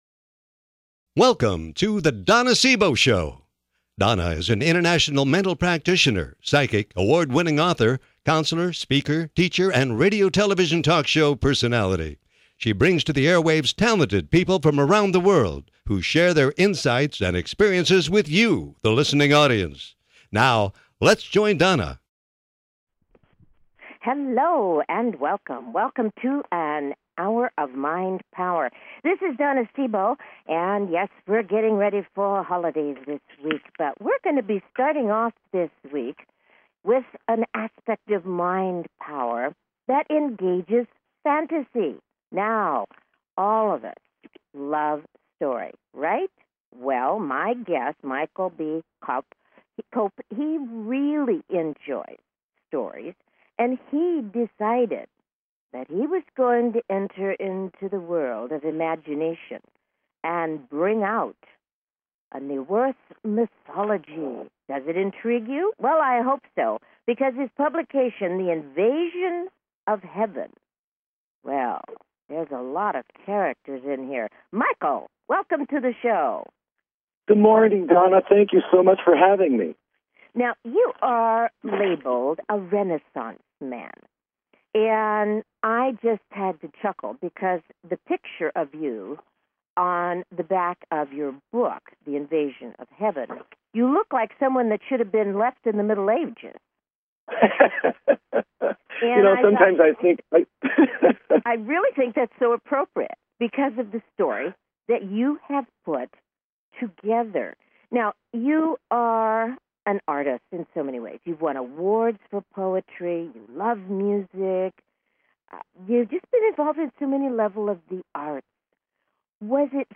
Talk Show Episode, Audio Podcast
Callers are welcome to call in for a live on air psychic reading during the second half hour of each show.